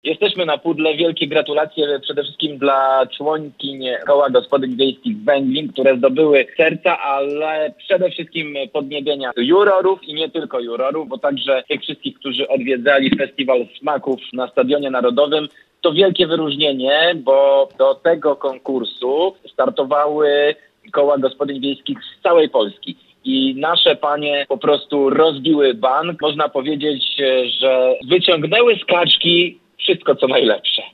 Smaczne święto z atrakcjami [ZDJĘCIA] Jak mówi sekretarz stanu w Kancelarii Premiera Jakub Stefaniak, na stoisku pań z Węglina, poza kaczką były przepyszne ciasta i cuda z malin: – Jesteśmy na „pudle”.